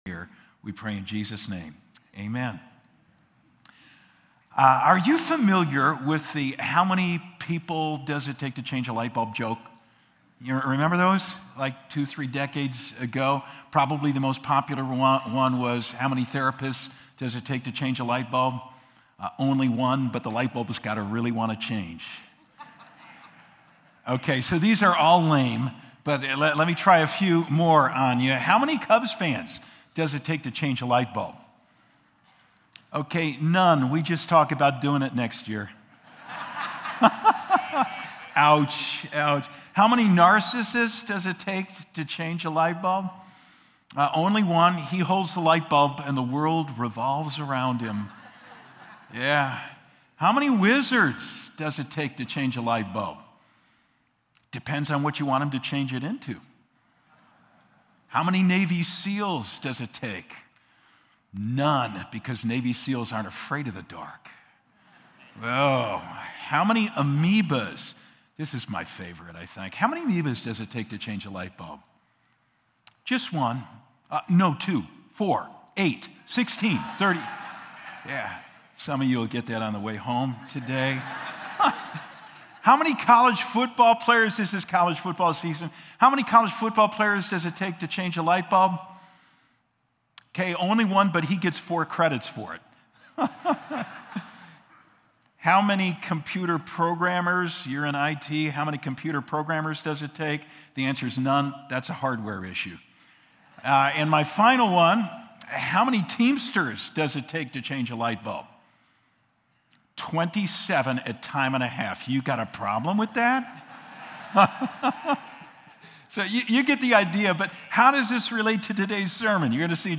11-3-24-Sermon.mp3